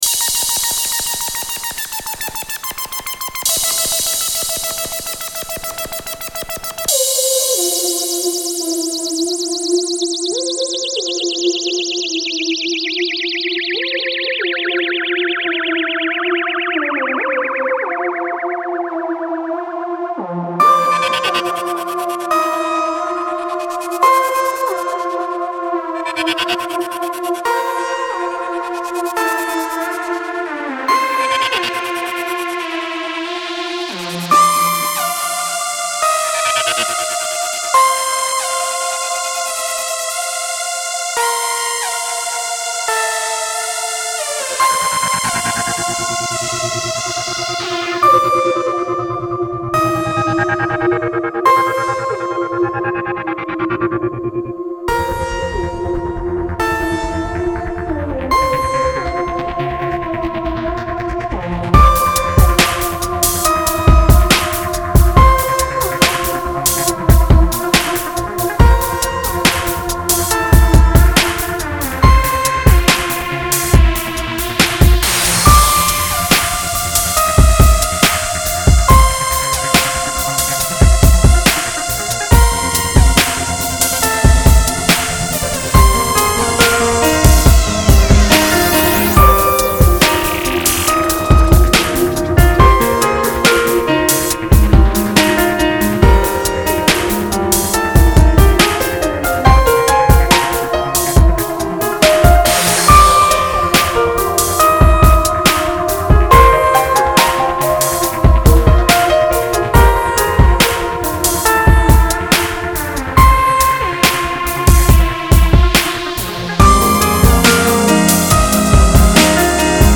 soundscape sound scape fx special fx